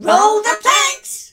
darryl_ulti_vo_02.ogg